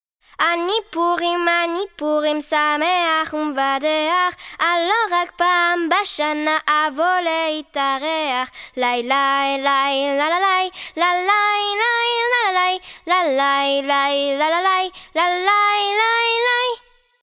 Audio Enfants: